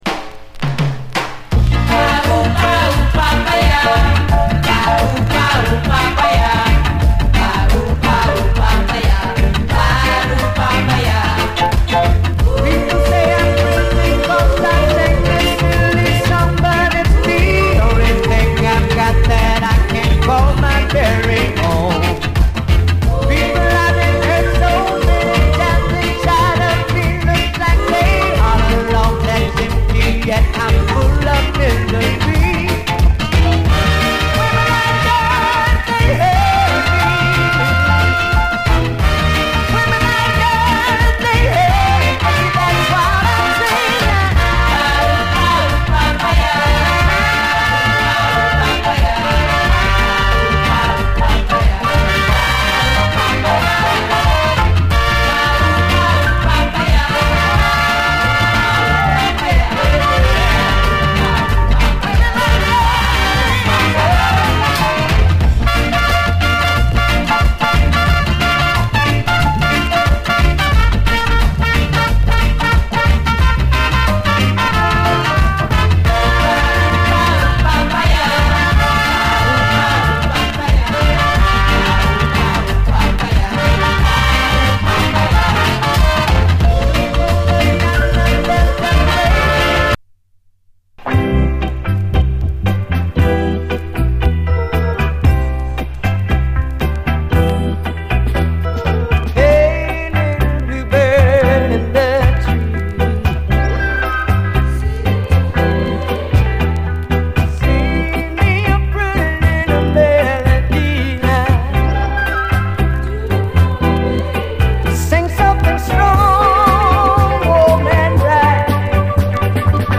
SOUL, 60's SOUL, REGGAE
60’Sジャマイカン・ソウル〜スキンヘッド・レゲエ！